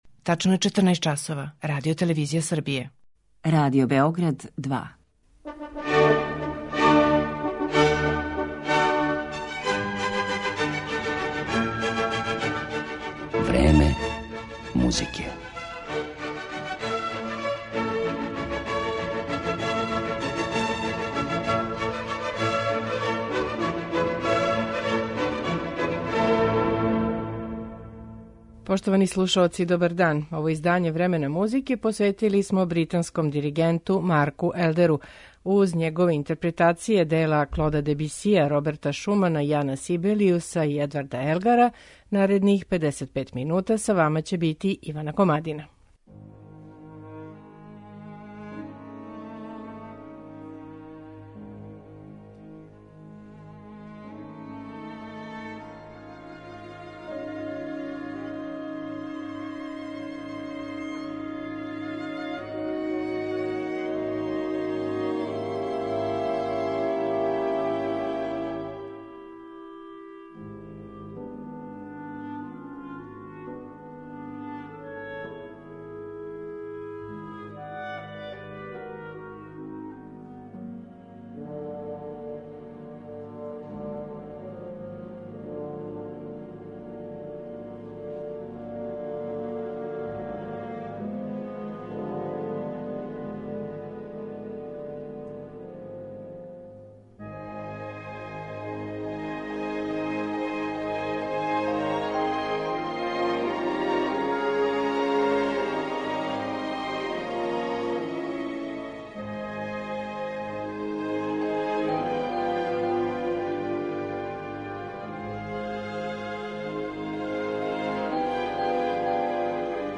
na koncertu
Jedino delo koje ćemo čuti a koje nije snimljeno u novembru jeste Klavirski koncert Roberta Šumana.